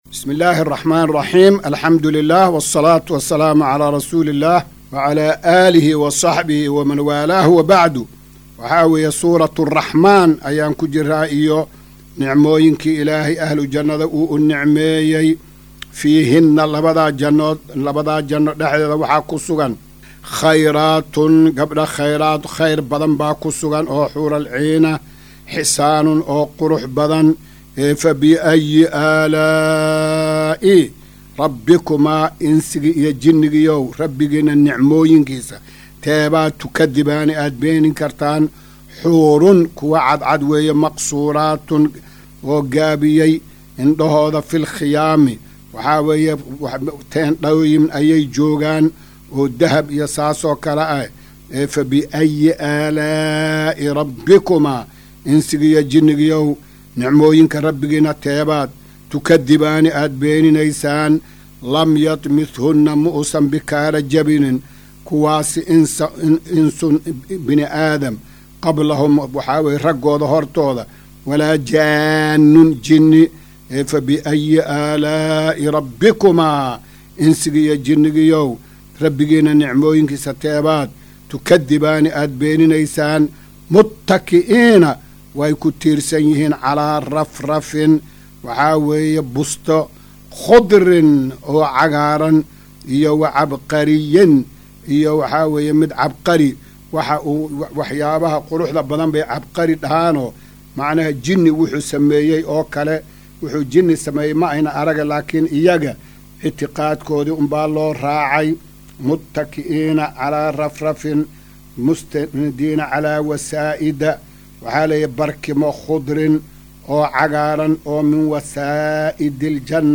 Maqal:- Casharka Tafsiirka Qur’aanka Idaacadda Himilo “Darsiga 254aad”